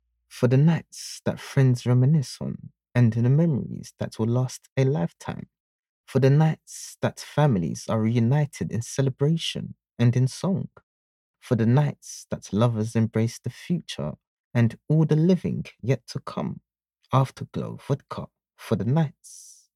Engaging, playful, warm, sexy, sultry, convincing, conversational.